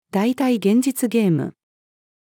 代替現実ゲーム-female.mp3